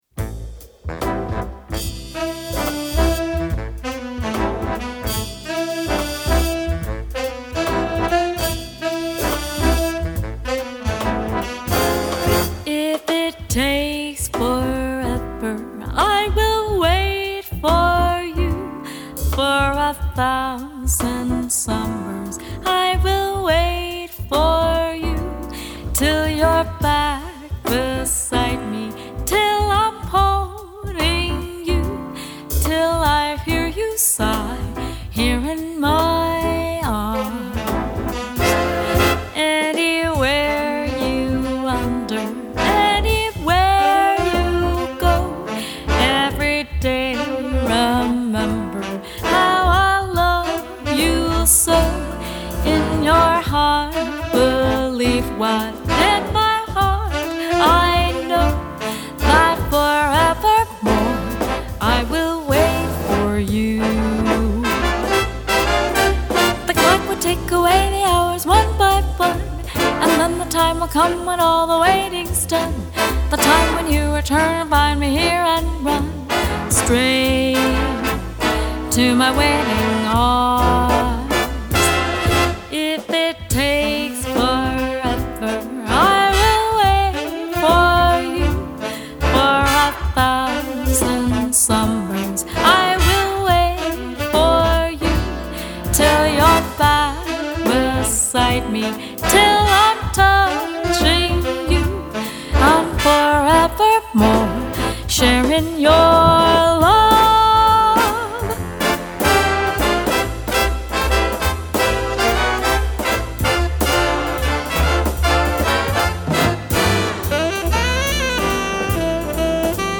Vocal Solo/ Jazz Ensemble Series Artist